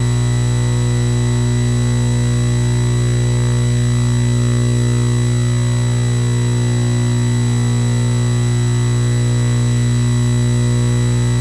Noise
After that, I set my inputs to max basically and recording the result. Since the results were very soft using NESA, I then increased the output post recording to +18dB from original.
NES-Noise-18dB.mp3